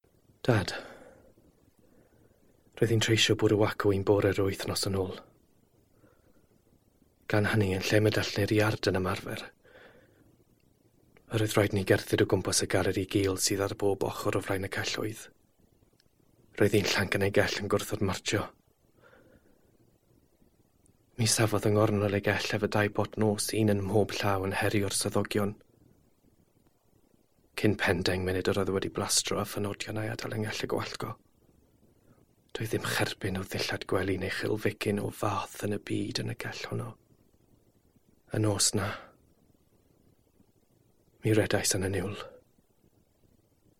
Welsh, Male, Home Studio, 20s-30s
07-Welsh-Language-Monologue.mp3